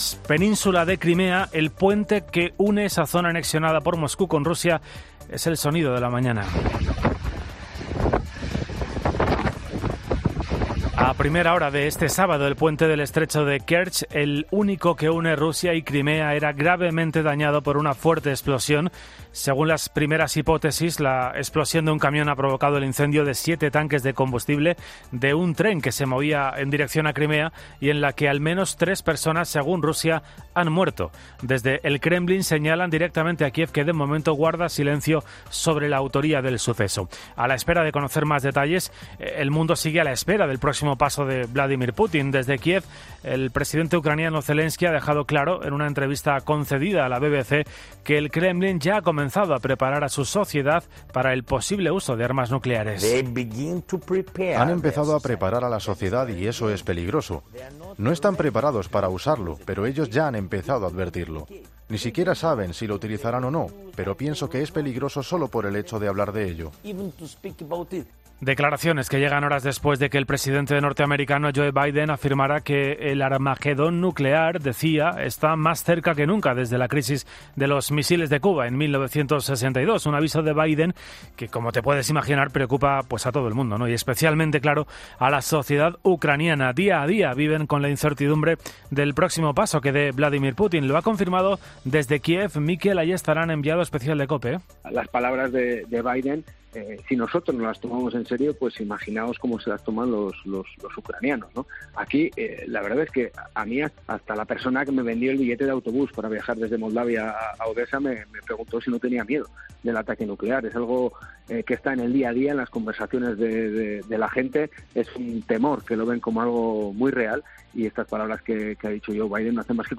Un experto analiza cómo va a afectar en la guerra en Ucrania la explosión del puente de Kerch